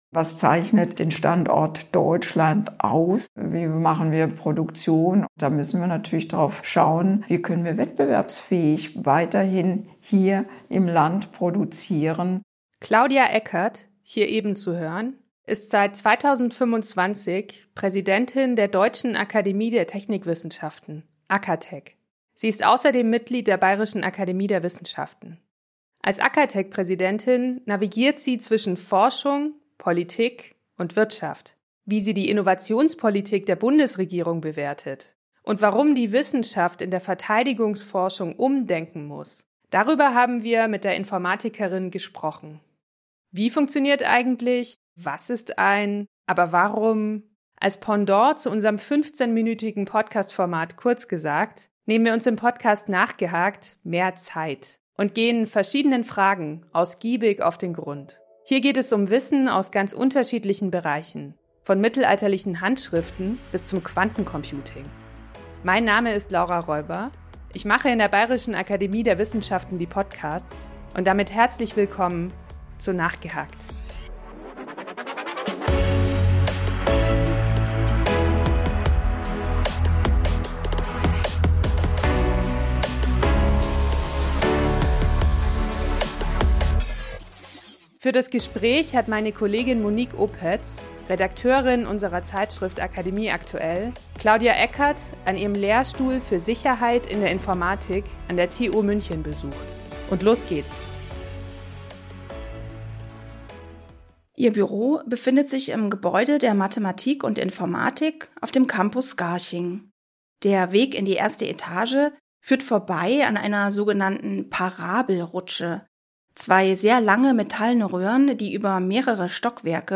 Was sie bei ihrer Arbeit an der Schnittstelle von Forschung, Politik und Wirtschaft bewegt, erzählt sie im Interview.